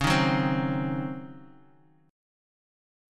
C#M7sus2sus4 chord